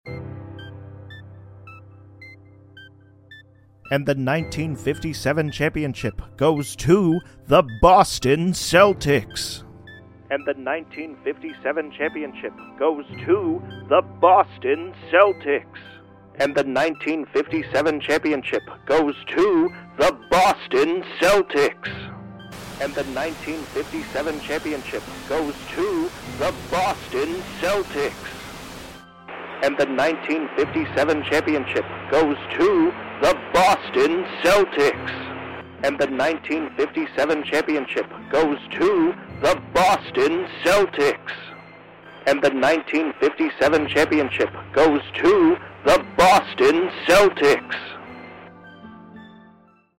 60s Second Design 1: Vintage sound effects free download
Vintage Radio Broadcast!